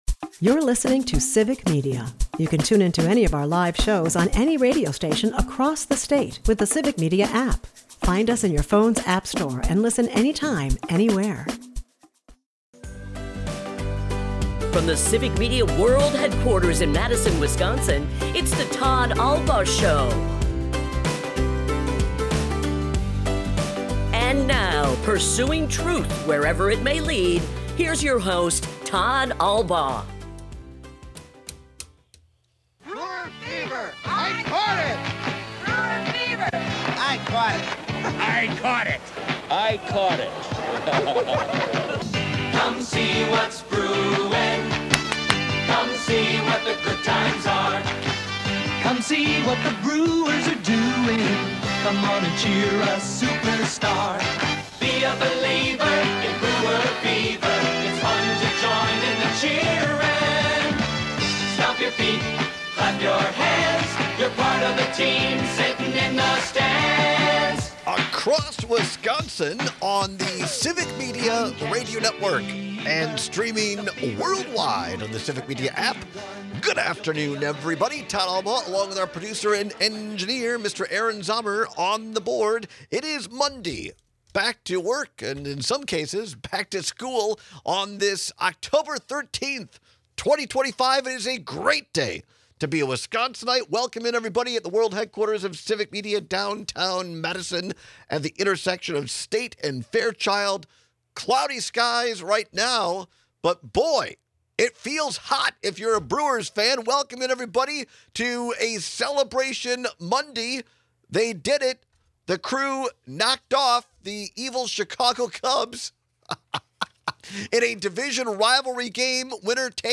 airs live Monday through Friday from 2-4 pm across Wisconsin